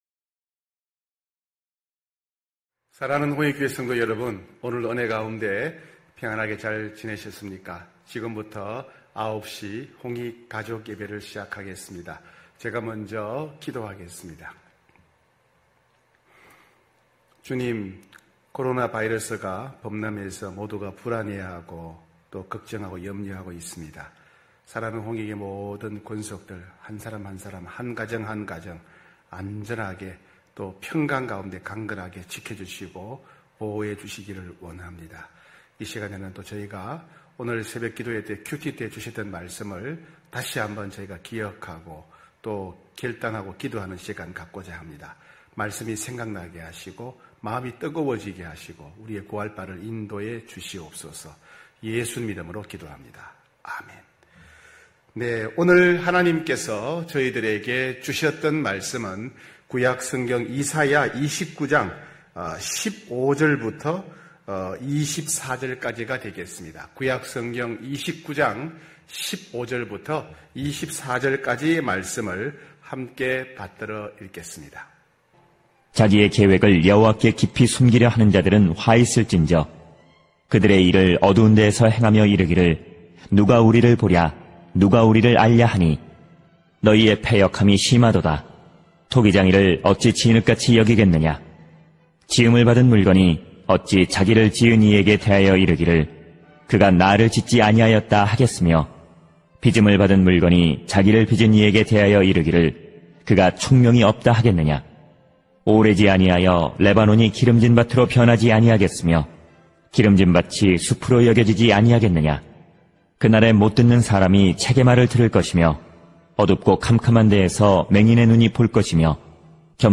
9시홍익가족예배(8월19일).mp3